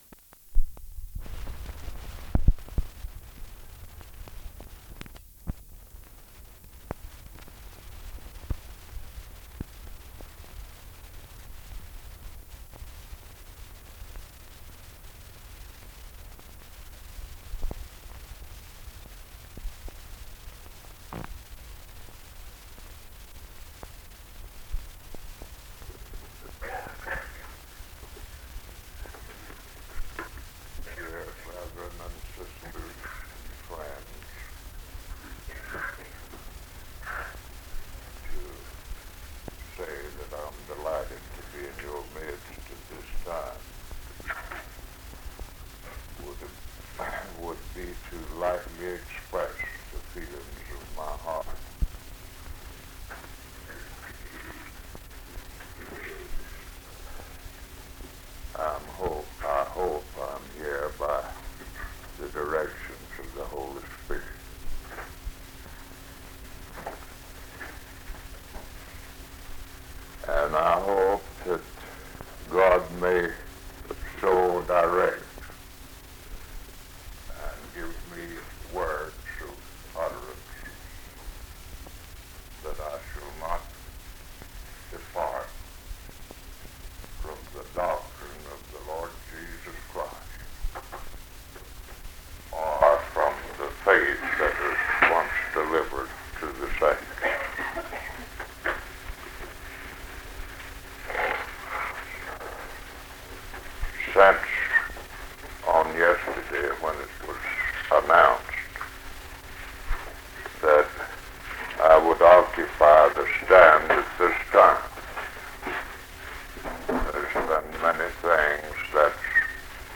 Primitive Baptists